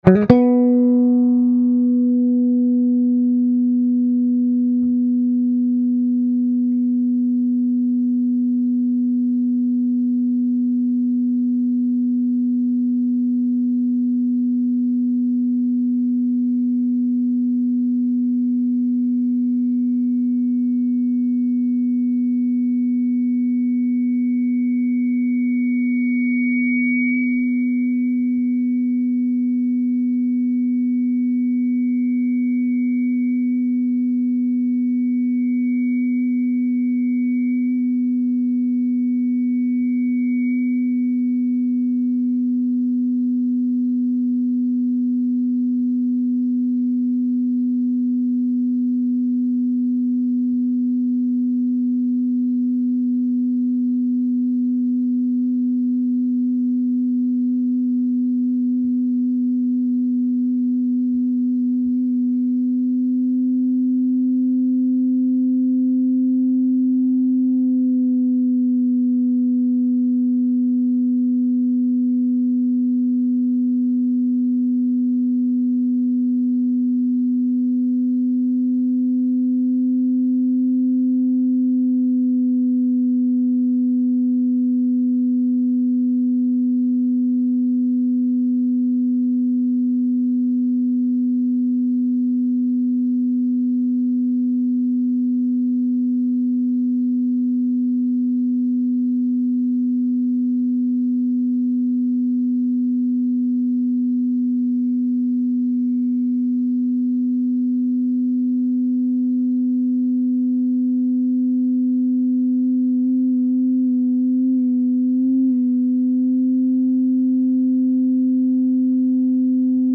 Compression
Unbelievably quiet, the Big Payback is one damned fine VCA-based, feed-back compressor boasting up to a 40:1 compression ratio, lightning-fast or molasses-slow response time (controlled via the Attack knob), and even a Dynamic-Expansion mode (with comp knob set to minimum)!
big-sustainback.mp3